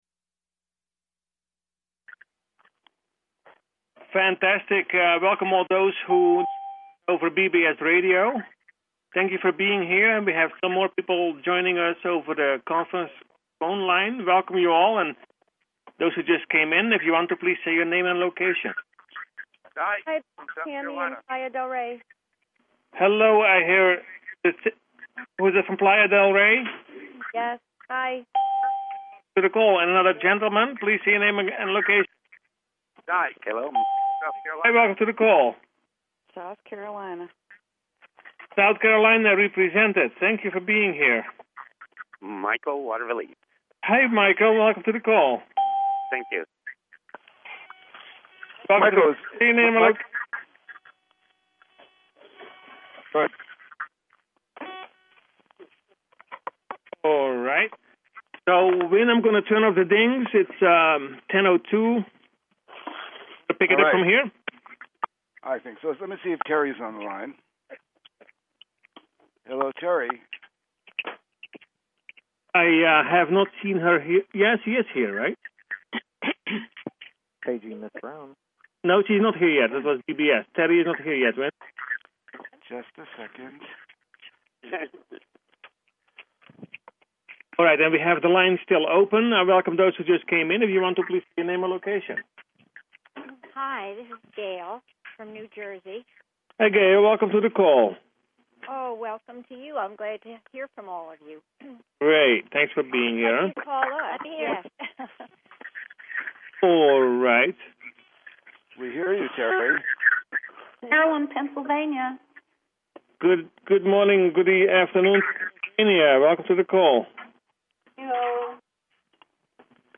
Personal Planetary Healing Meditation